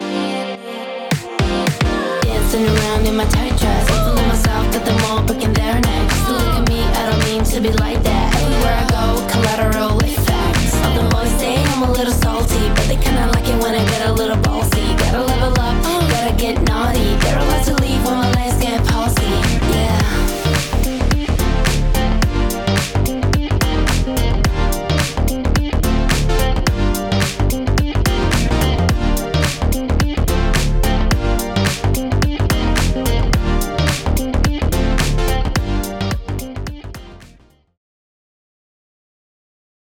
今回はシンプルな構成のデモ曲（ドラム、ベース、ギター、ボーカル）を使って、各機能を確認していきます。
OFF（楽曲全体）